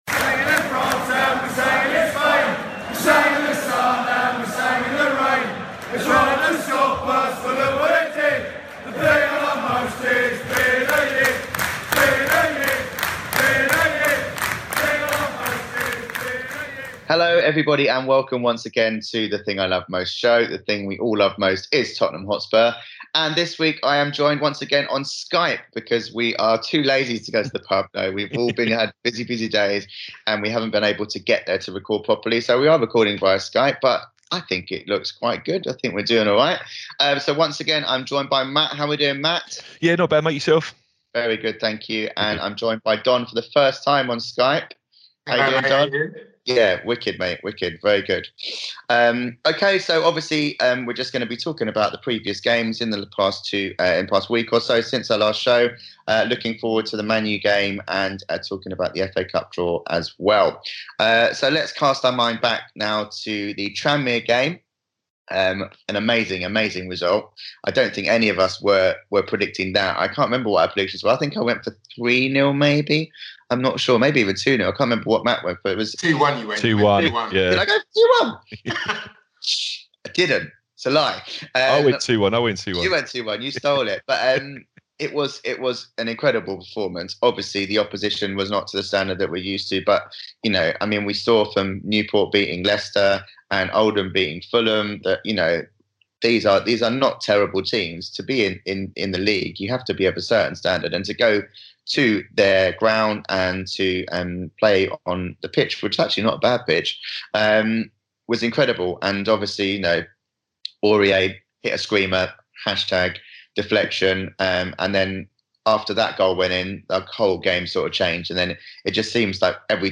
In this week's Skype show,